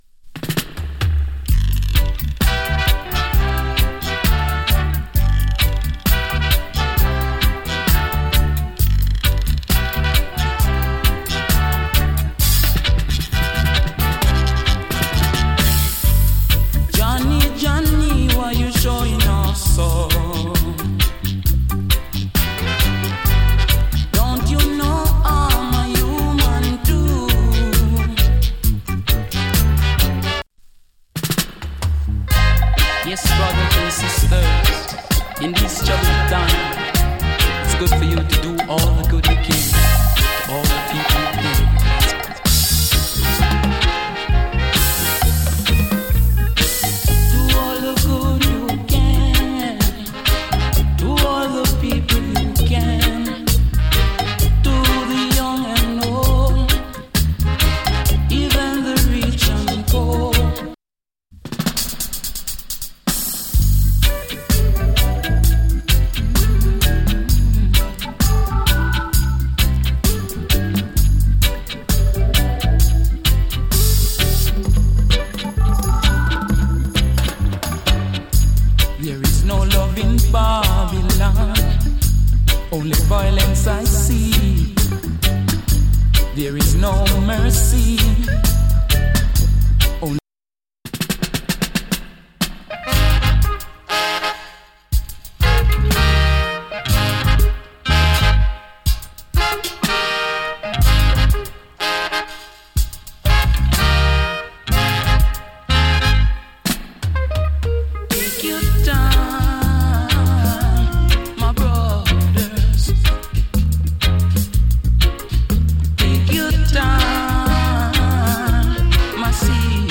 チリ、パチノイズ極わずかに有り。
の牧歌的で美しいコーラスの REGGAE. ROOTS REGGAE ALBUM !